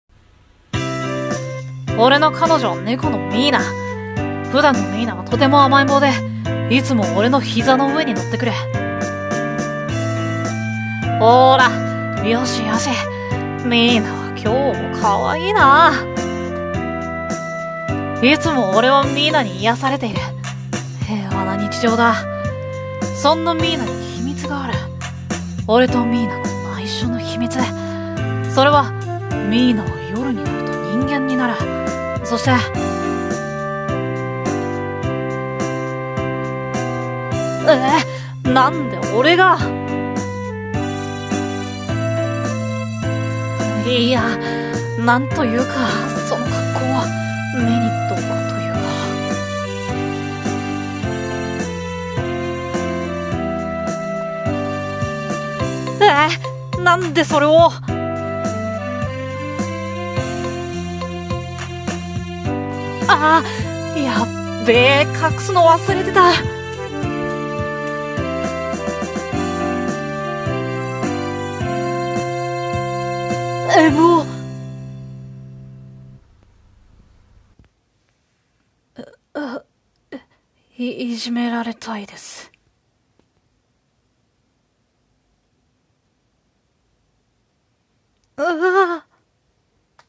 声劇台本ギャグ｢俺の彼女はネコ🐈｣コラボ募集中